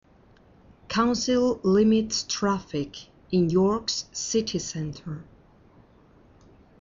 Escucha a la Redactora Jefe y completa las noticias con las siguientes palabras: